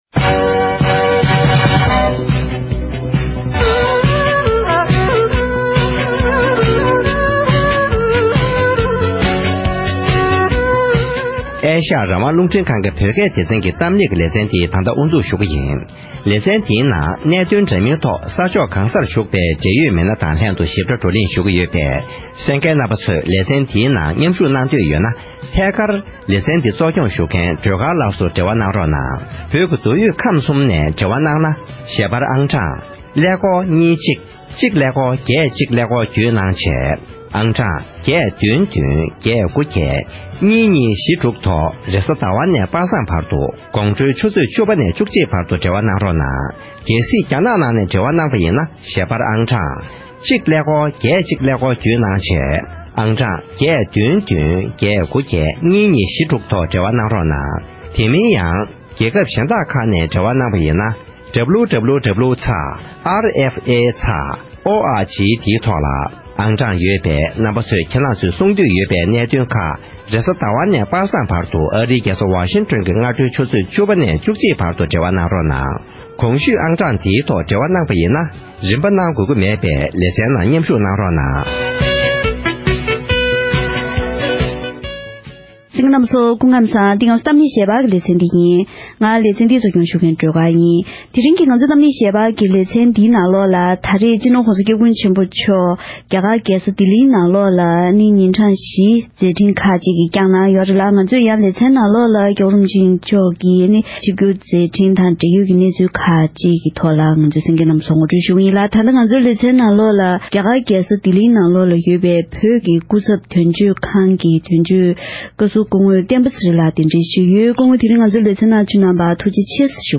ཞིབ་ཕྲའི་མཛད་འཕྲིན་ཁག་གི་ཐོག་ལྡི་ལི་བོད་ཀྱི་དོན་གཅོད་ཁང་གི་དོན་གཅོད་བཀའ་ཟུར་བསྟན་པ་ཚེ་རིང་ལགས་སུ་བཀའ་འདྲི་ཞུས་པ་ཞིག་གསན་རོགས་གནང་།